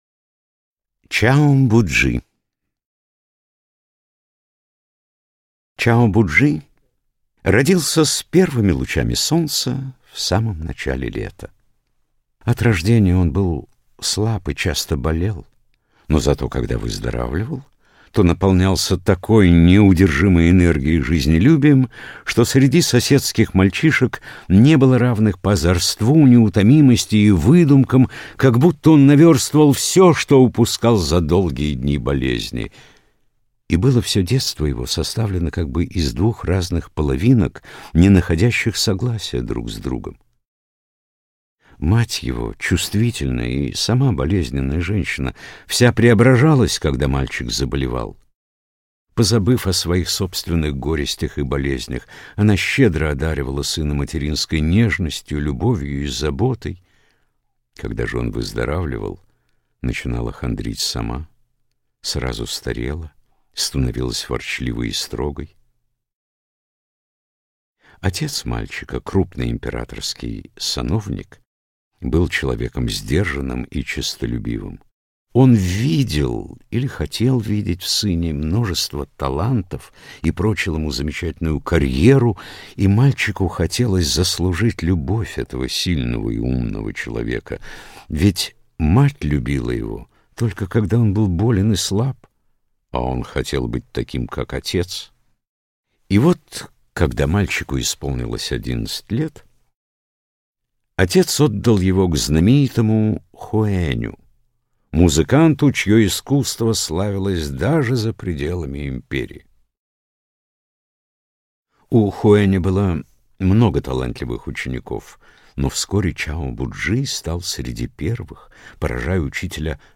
Аудиокнига Дзенские притчи | Библиотека аудиокниг